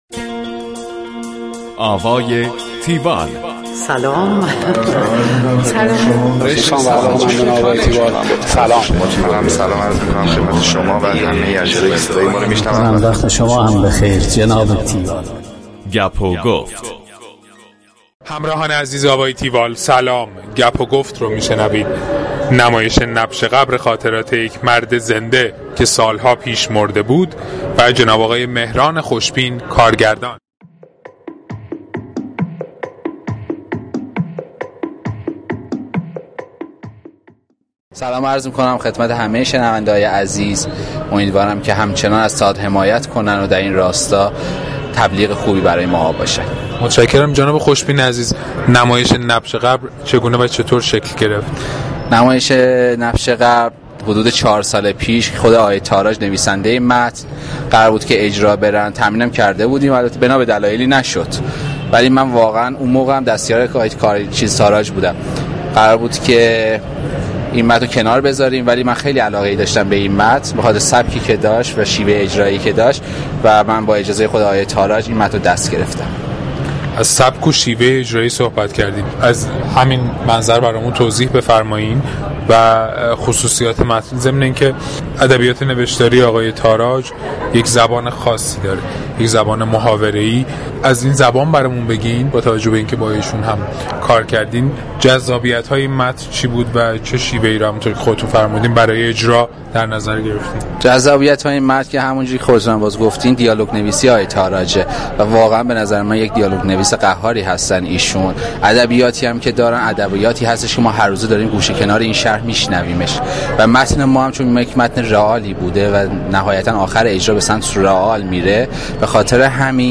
رئال بهترین شکل نمایشی برای این درام هست گفتگوی تیوال